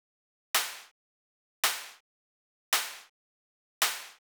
03 Clap.wav